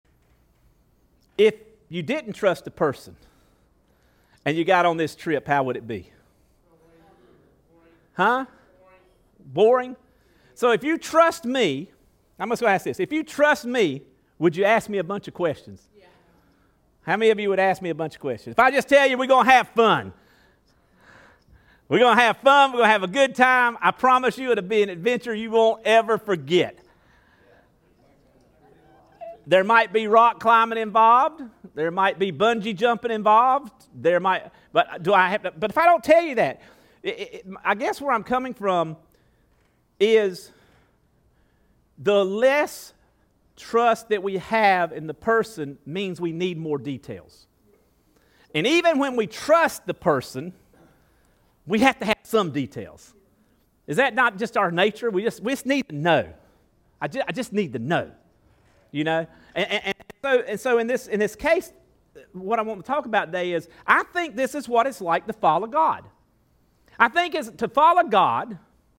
Additionally, we apologize because, as you will notice, the first bit of the sermon was not recorded.